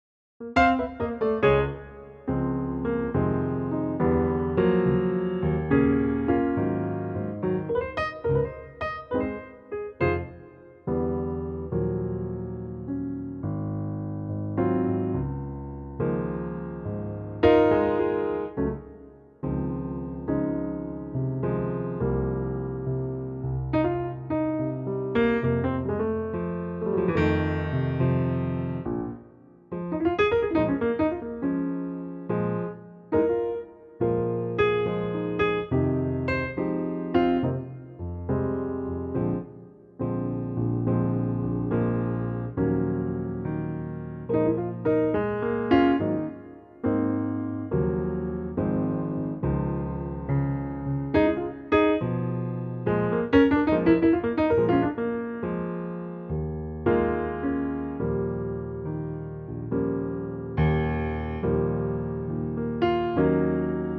key - Db - vocal range - Eb to Gb
Superb piano only arrangement